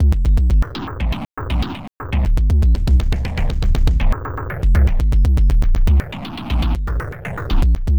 And a few crazy loops, mostly done with random kits and functions.